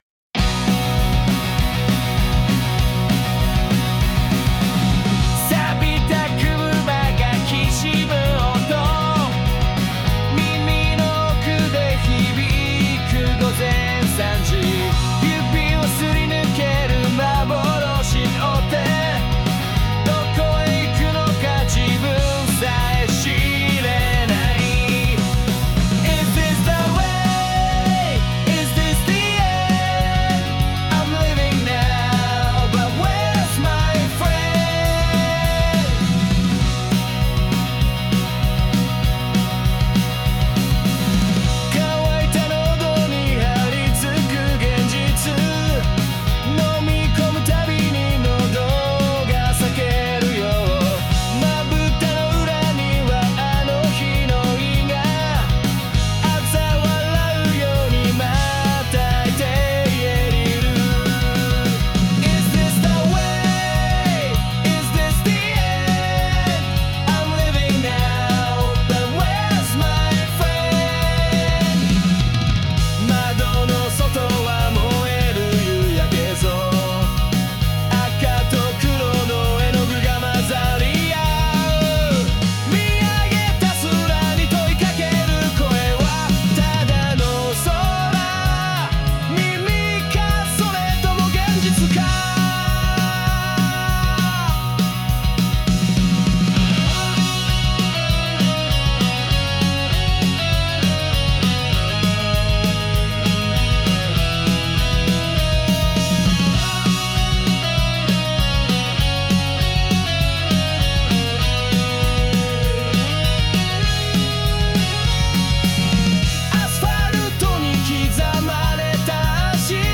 ＊この楽曲は有料版SNOW AIを使って創作しました。